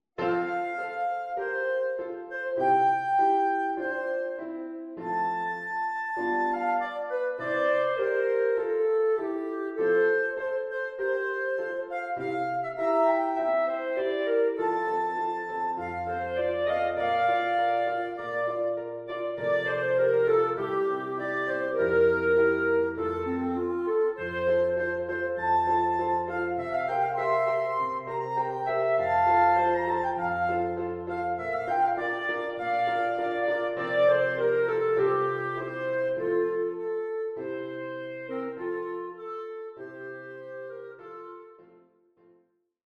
Listen to an excerpt from the two clarinet version…